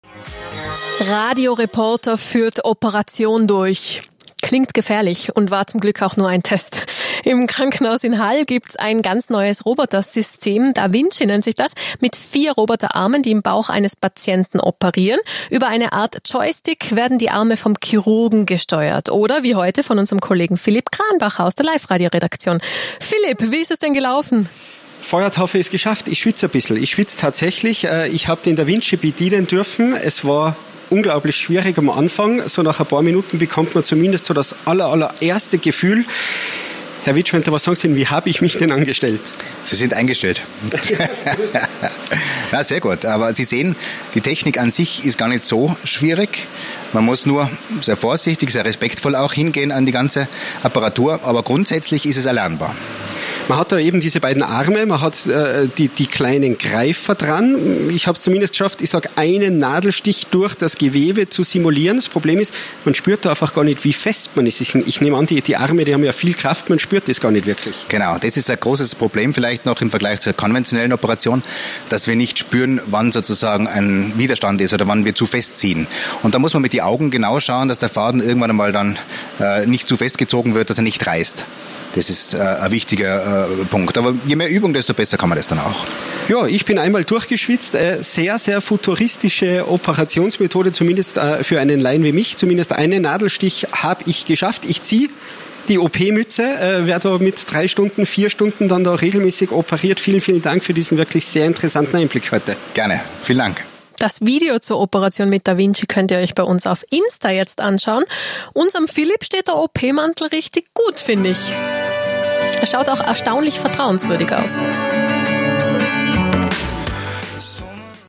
Auch Liferadio Tirol hat uns im LKH Hall besucht und einen Beitrag direkt aus dem OP gesendet.